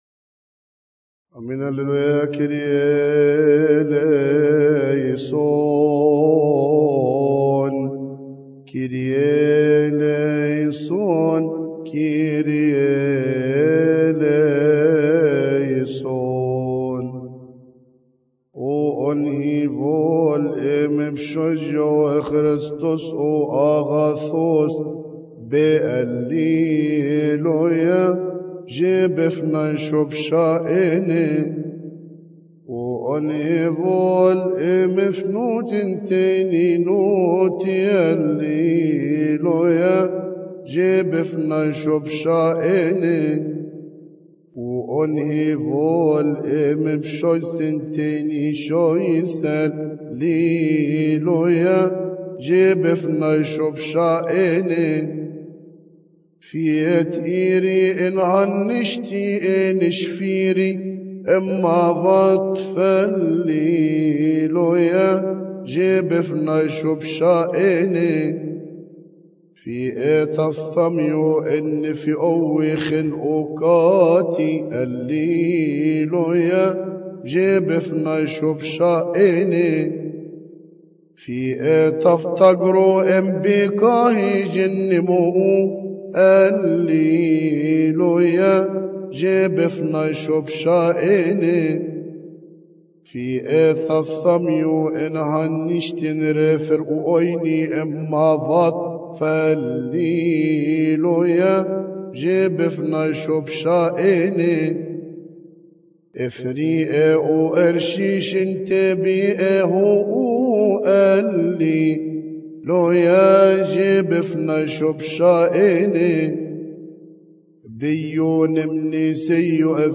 المرتل